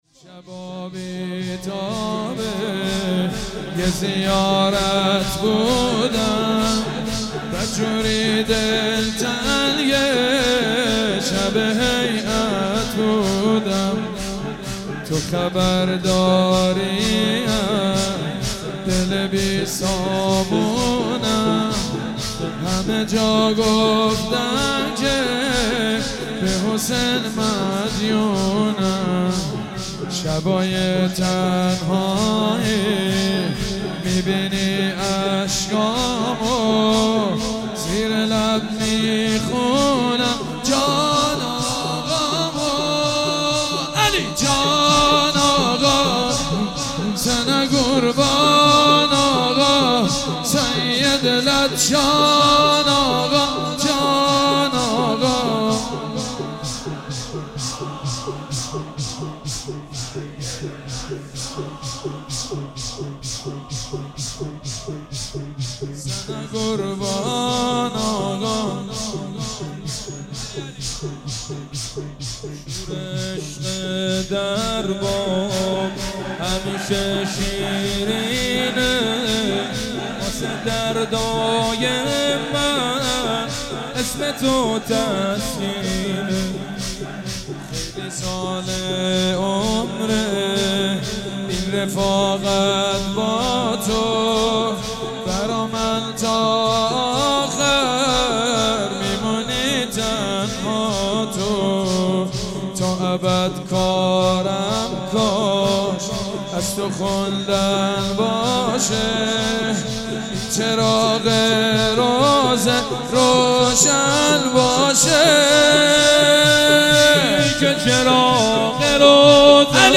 مداحی حاج حسین سیب سرخی و حاج مجید بنی فاطمه در خمینی شهر